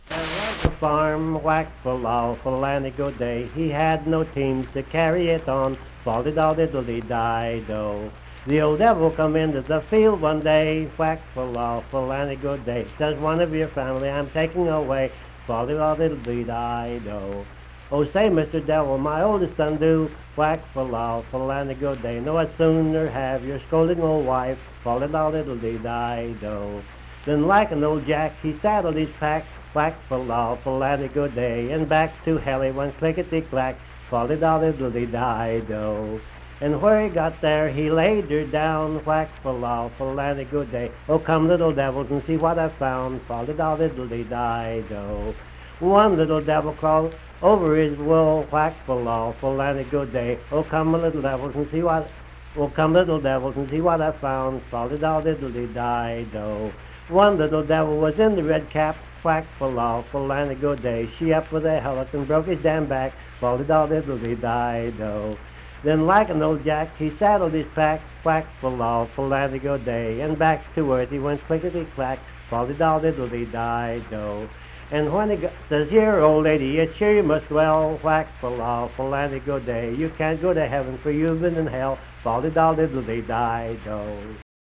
Unaccompanied vocal music
Performed in Hundred, Wetzel County, WV.
Voice (sung)